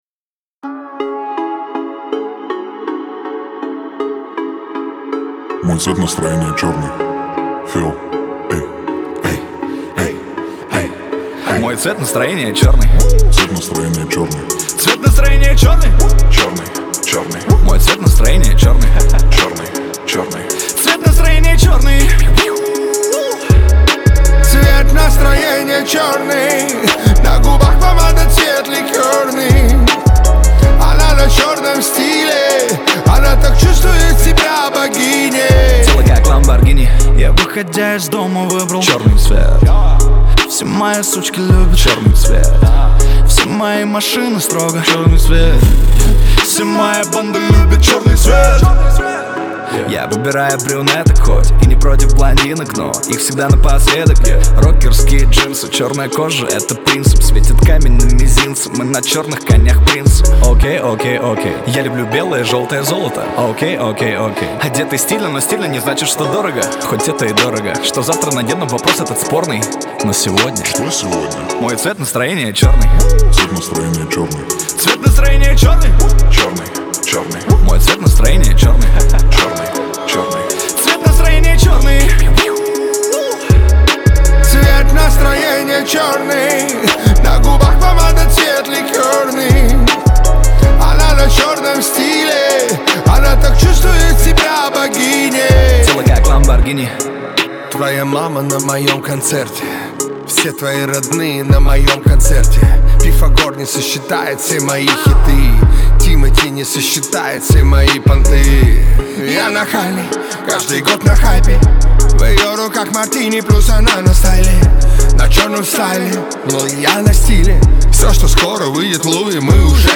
это яркая и запоминающаяся композиция в жанре поп